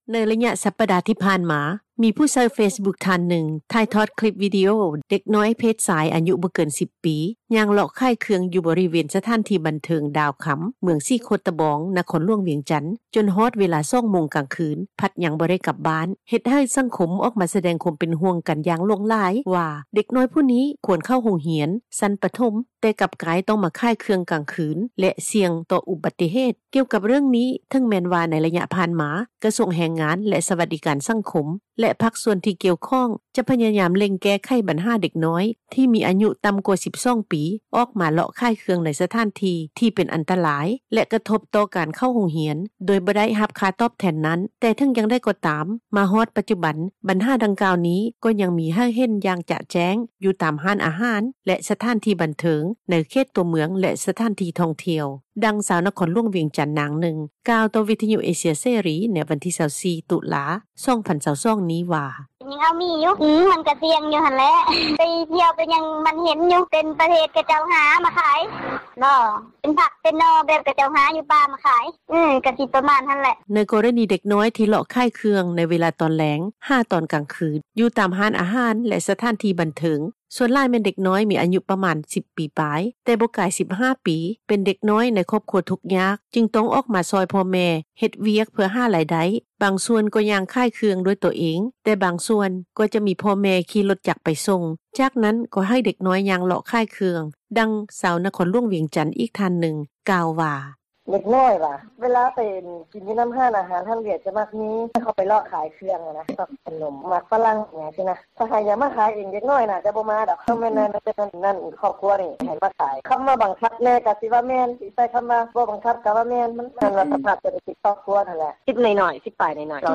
ດັ່ງຊາວນະຄອນຫລວງວຽງຈັນ ນາງນຶ່ງ ກ່າວຕໍ່ວິທຍຸ ເອເຊັຽເສຣີ ໃນມື້ວັນທີ24 ຕຸລາ 2022 ນີ້ວ່າ:
ດັ່ງຊາວນະຄອນຫລວງວຽງຈັນ ອີກທ່ານນຶ່ງ ກ່າວວ່າ:
ດັ່ງ ເຈົ້າໜ້າທີ່ ທີ່ເຮັດວຽກ ດ້ານສຸຂພາບ ແລະ ຄວາມປອດພັຍ ຂອງເດັກນ້ອຍ ຜູ້ບໍ່ປະສົງອອກຊື່ ແລະ ຕຳແໜ່ງທ່ານນຶ່ງ ກ່າວວ່າ: